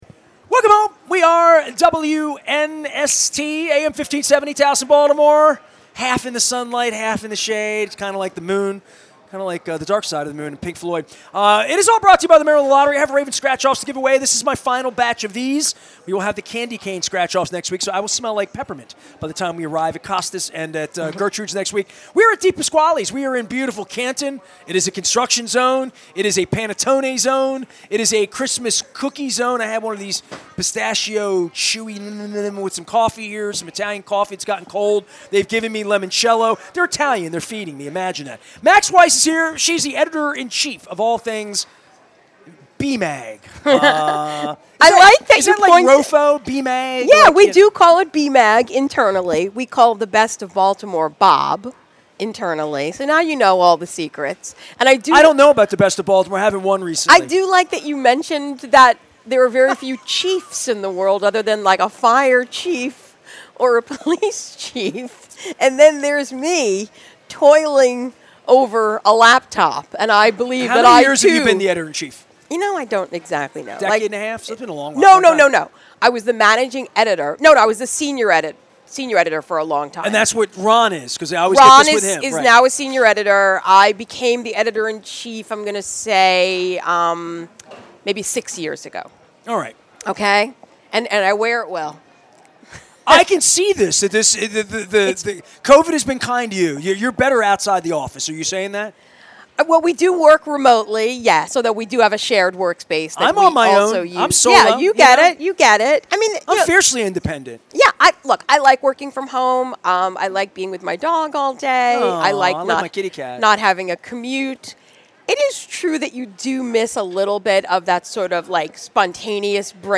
at DiPasquale's in Canton